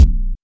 kick 31.wav